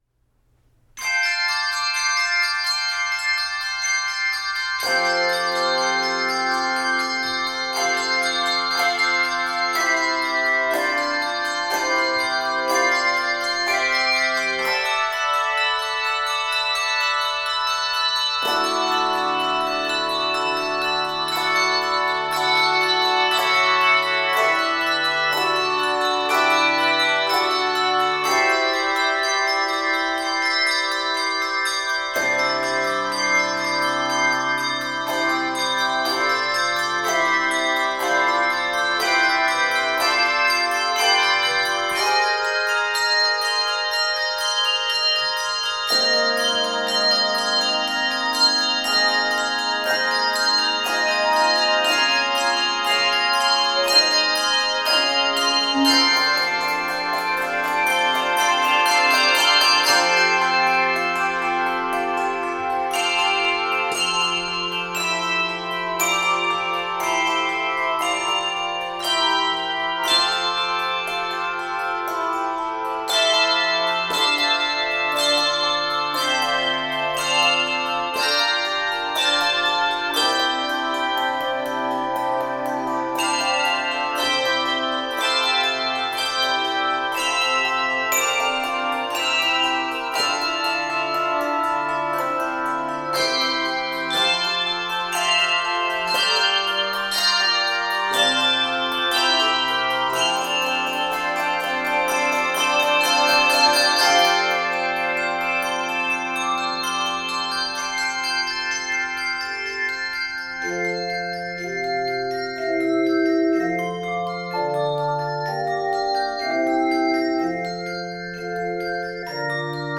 This energetic and joyous arrangement